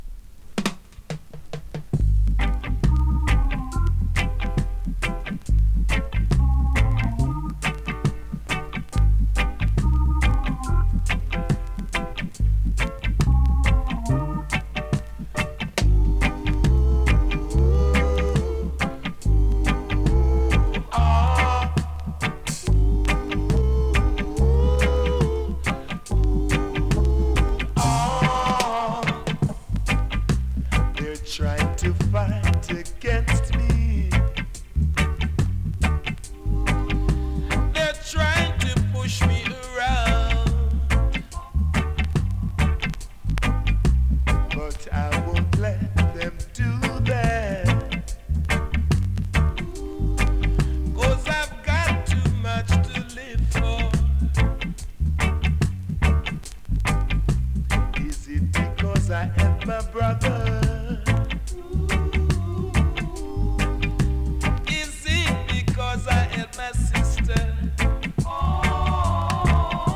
コメントMEGA RARE レゲエ!!
スリキズ、ノイズかなり少なめの